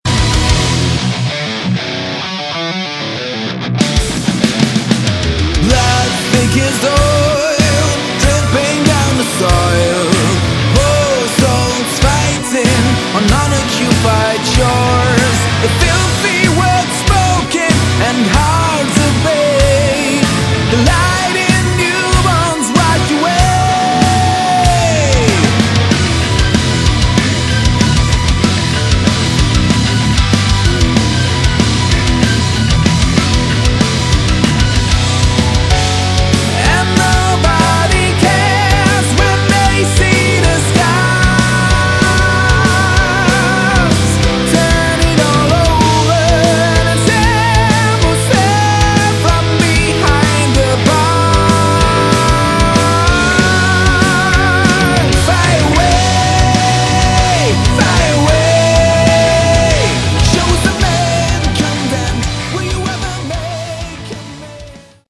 Category: Prog Rock/Metal
vocals, additional guitars
keyboards
guitars
bass
drums